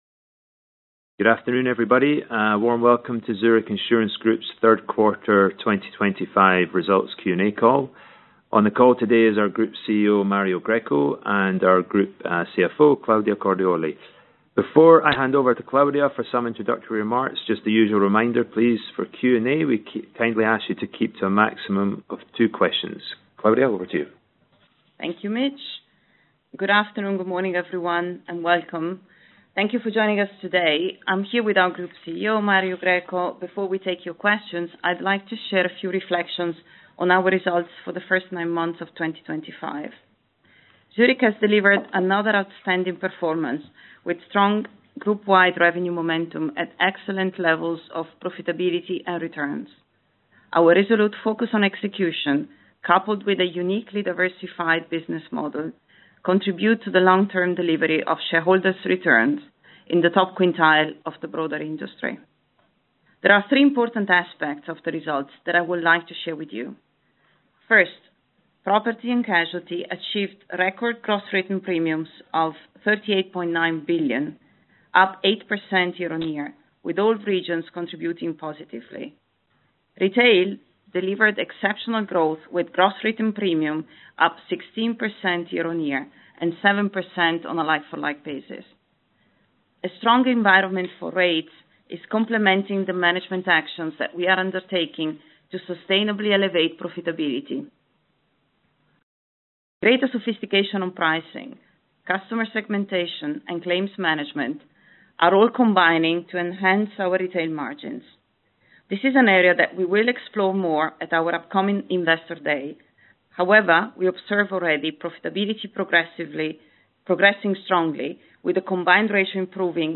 Q&A Analyst Conference Call Podcast - opens in a new window